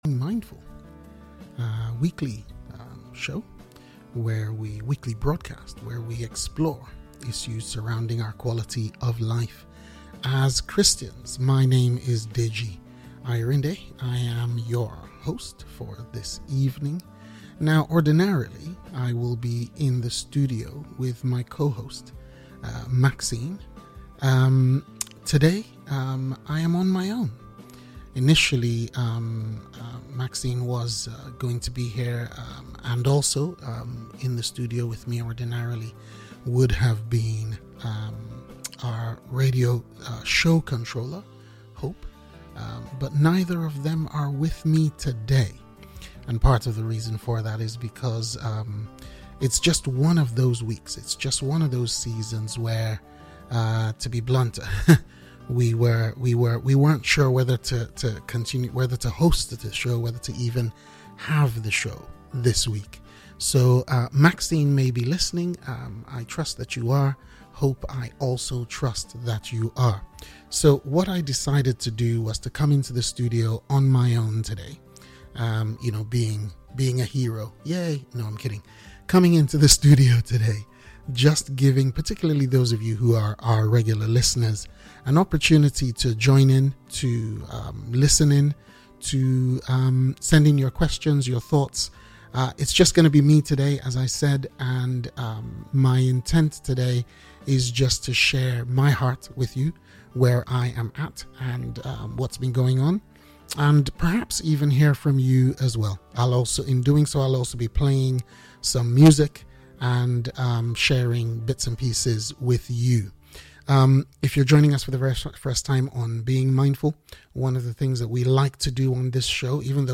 Click to listen or download a recording of this live show.